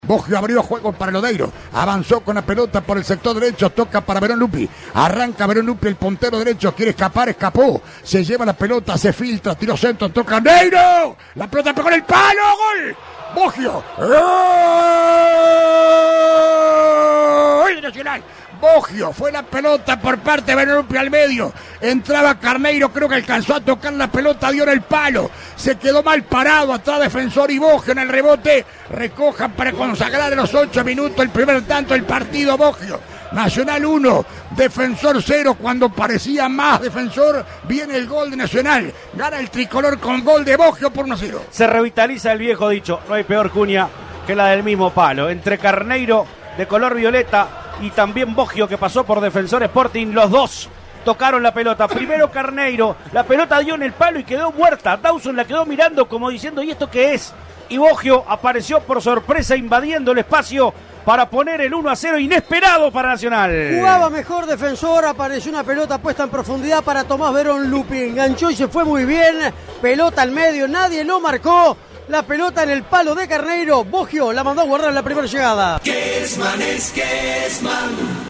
GOLES RELATADOS POR ALBERTO KESMAN